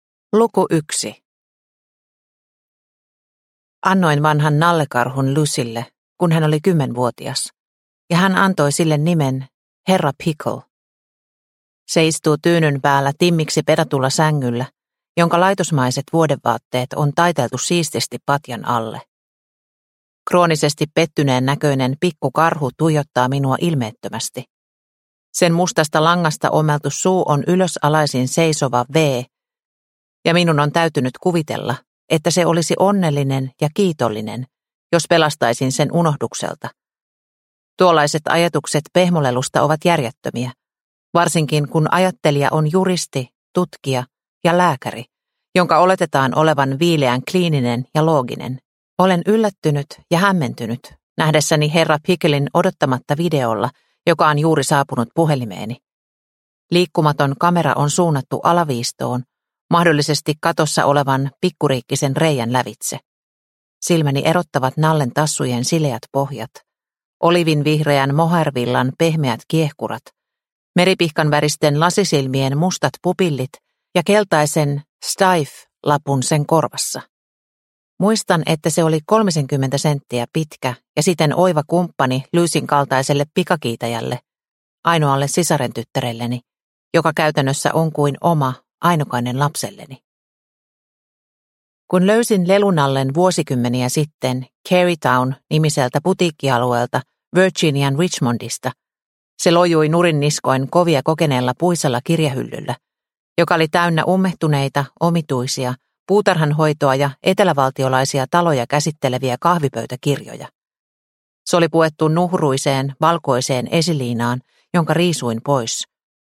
Riistetty – Ljudbok – Laddas ner